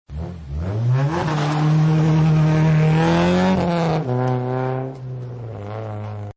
Звуки гоночных машин
Рев гоночного автомобиля при разгоне и удалении